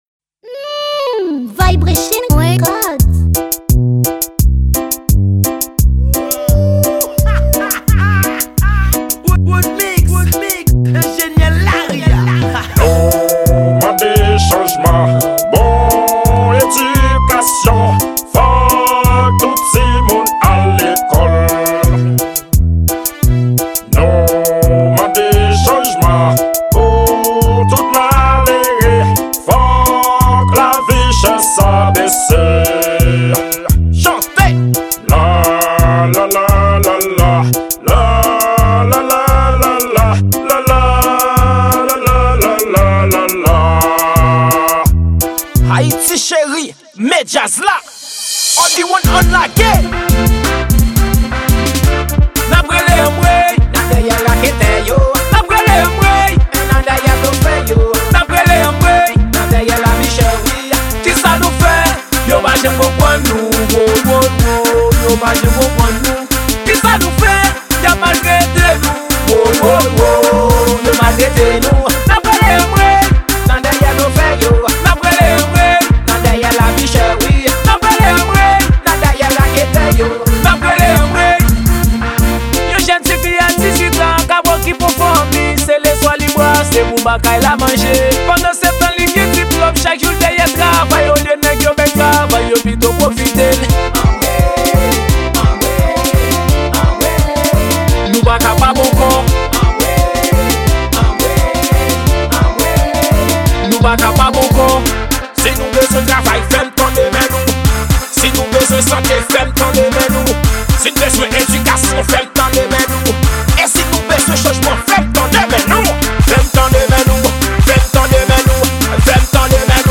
Genre : K-naval